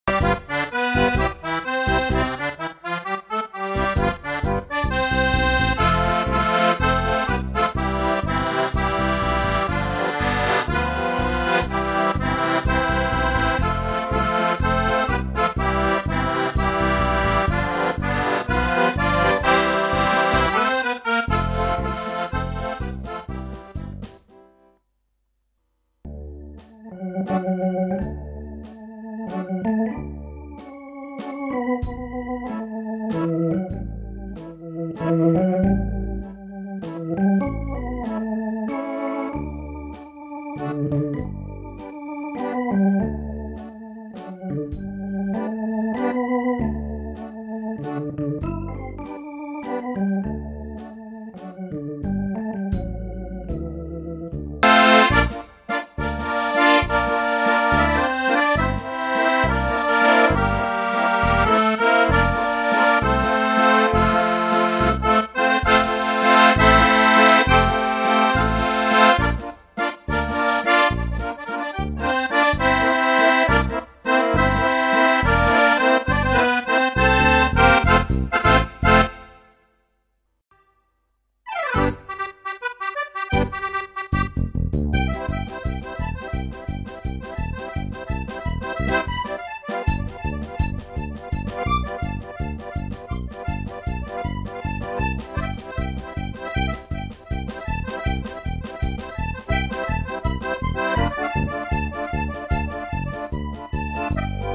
Akustik auf dem V-Akkoreon
Die Aufnahmen stammen noch aus dem Jahr 2012 - aufgenommen auf meinem alten Tyros 2 Keyboard von Yamaha nach dem Motto: